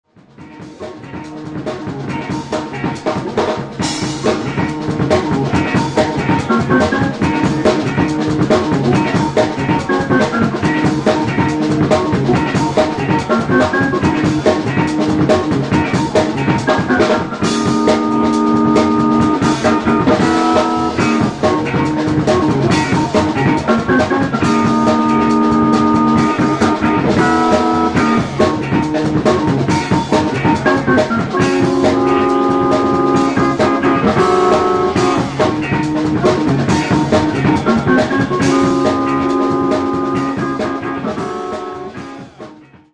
• Funky Soul-jazz jams galore!
• GreaZy Hammond B-3 organ grooves.